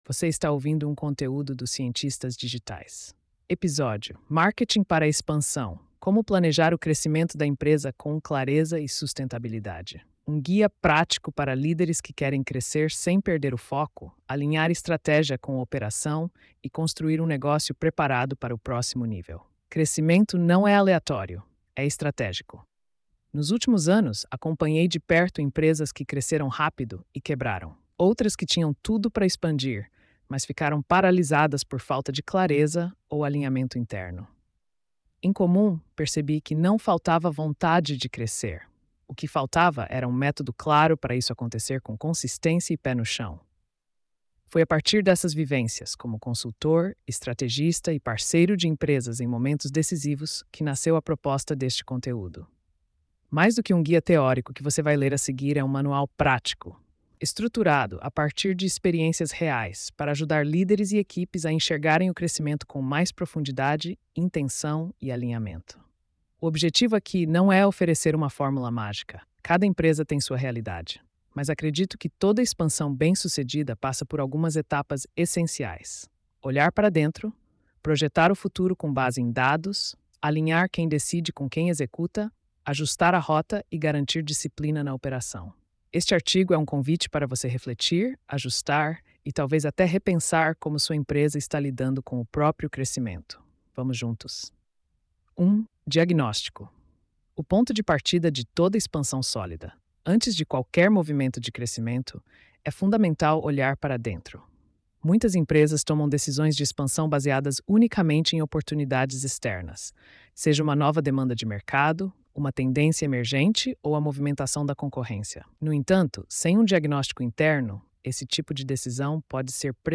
post-3296-tts.mp3